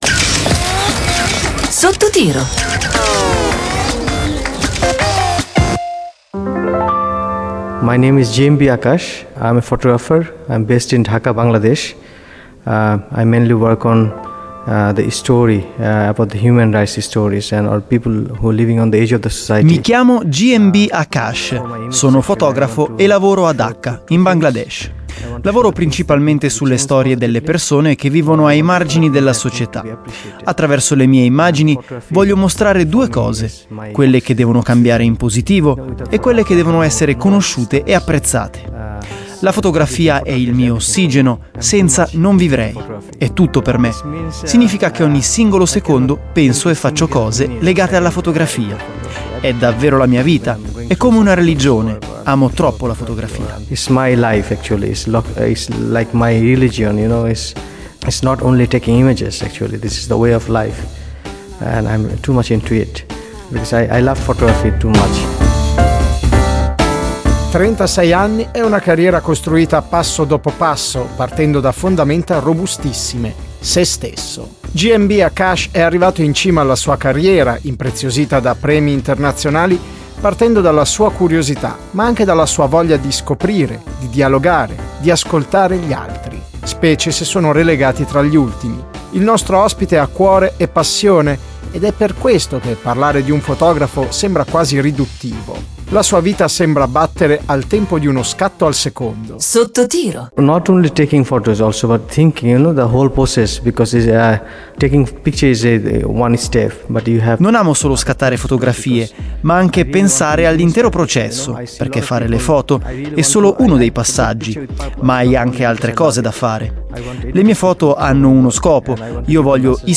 ReteTre intervista GMB Akash